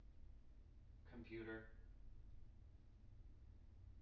wake-word
tng-computer-186.wav